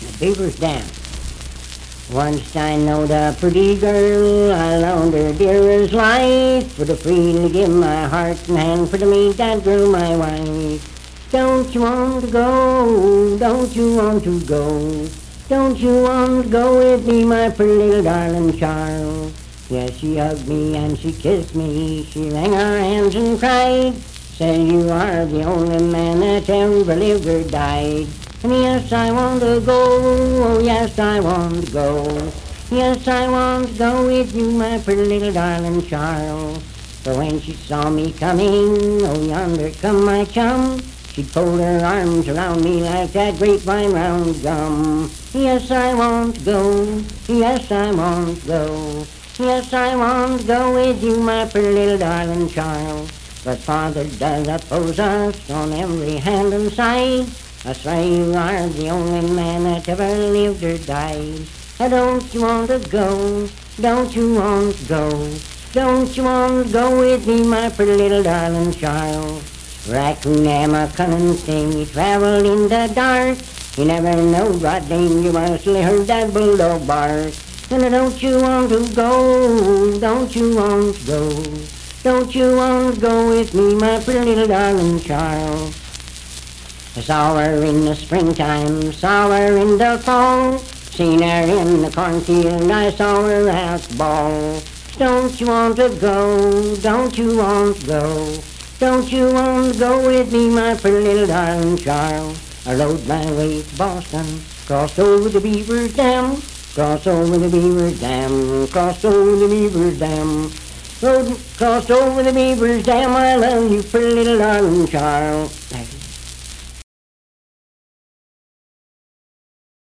Unaccompanied vocal music
Verse-refrain 7(4)&R(4). Performed in Sandyville, Jackson County, WV.
Miscellaneous--Musical, Love and Lovers, Bawdy Songs
Voice (sung)